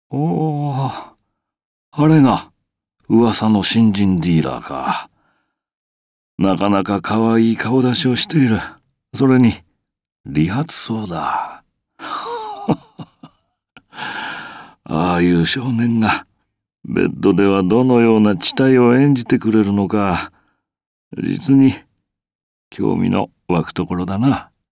ブラウン　　　ＣＶ：土師孝也